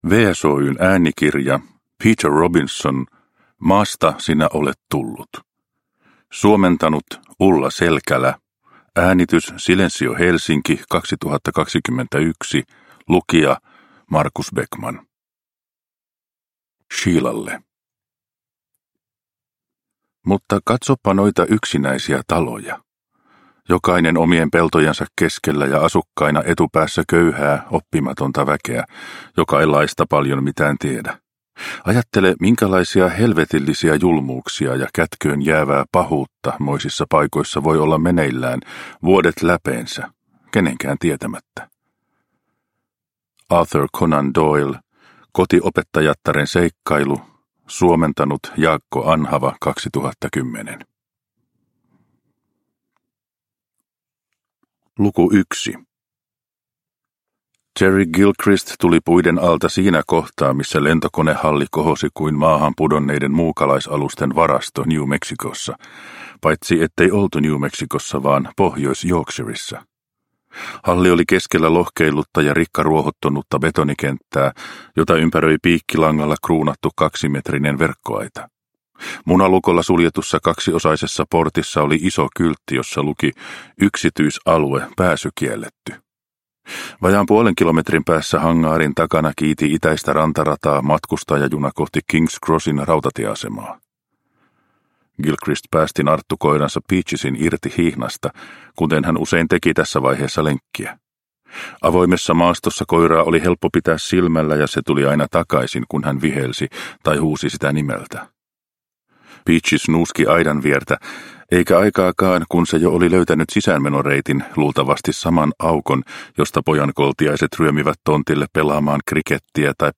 Maasta sinä olet tullut – Ljudbok – Laddas ner